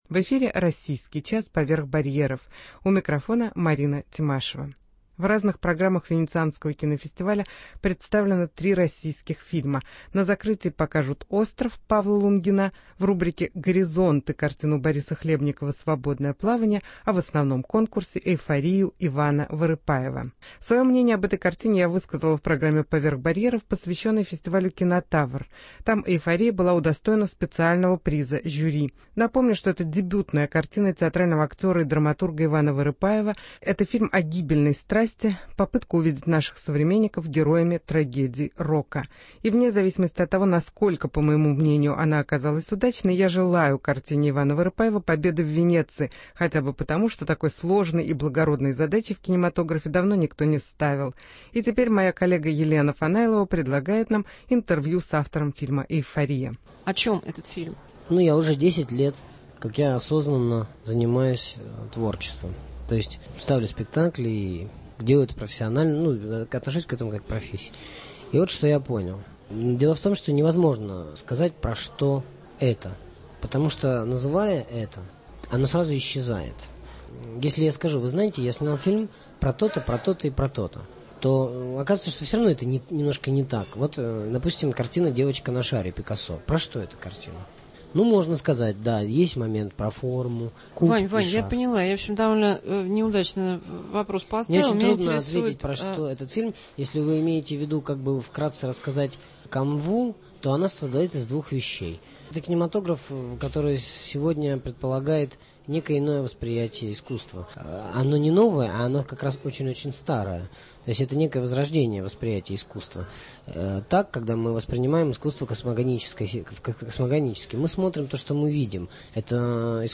интервью с Иваном Вырыпаевым